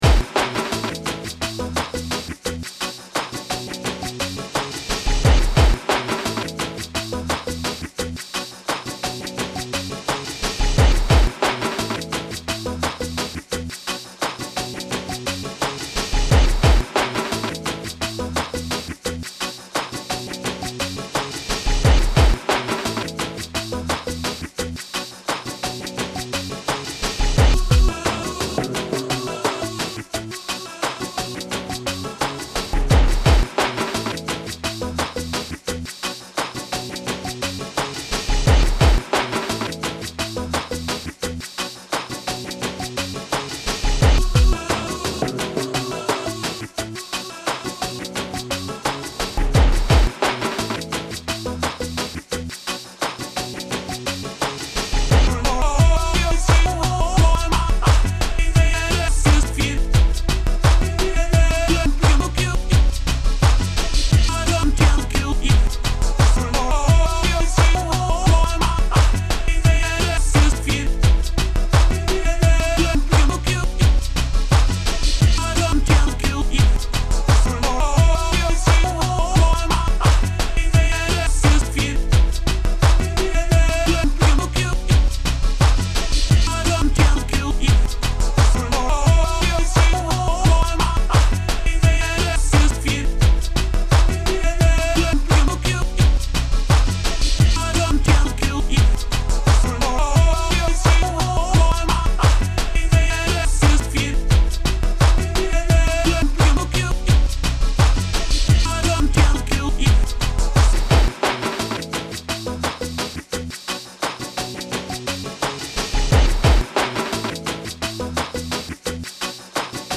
OK.. perhaps it is not pop. but it sure is damned close